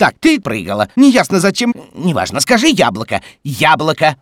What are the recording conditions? This is an audio clip from the game Portal 2 .